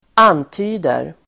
Uttal: [²'an:ty:der]